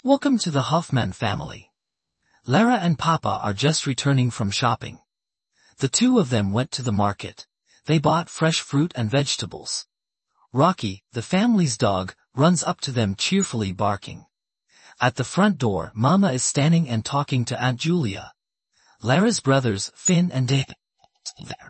So I've tried using that model and I was really surprised how good the results were: ![#4, English,
4_eng_expressive.mp3